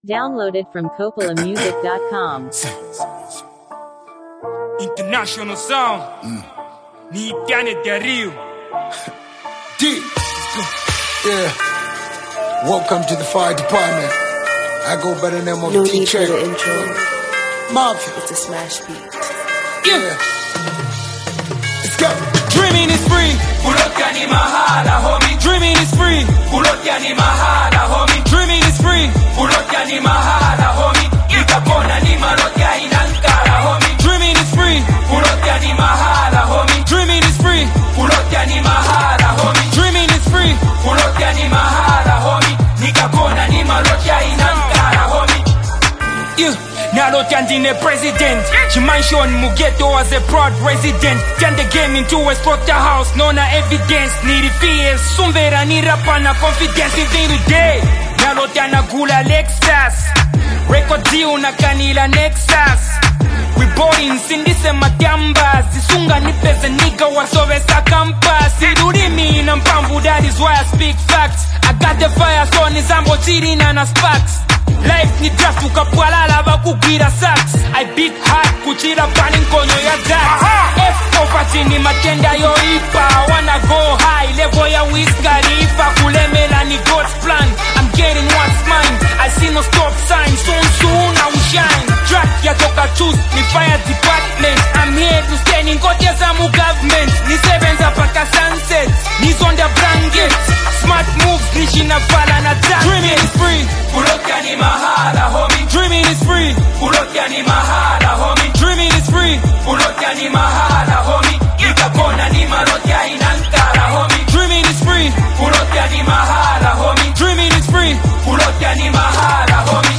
an uplifting and motivational song
With inspiring lyrics and a heartfelt delivery